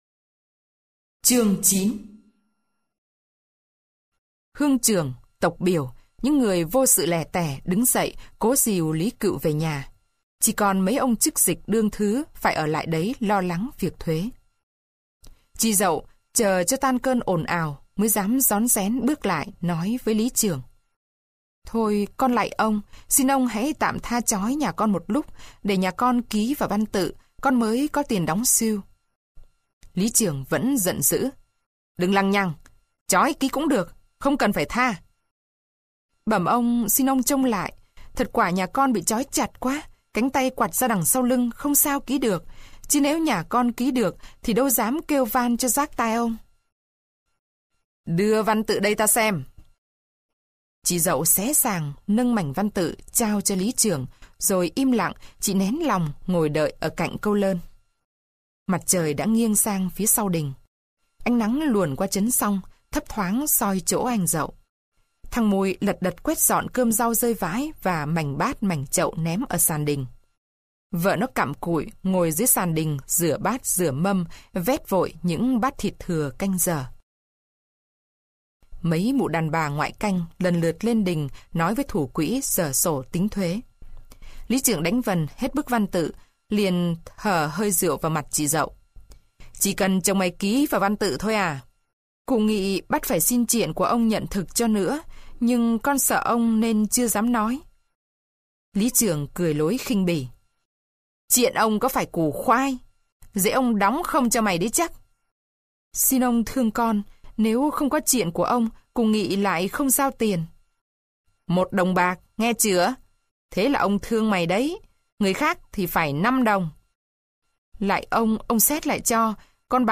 Sách nói | Tắt đèn 6+7+8+9+10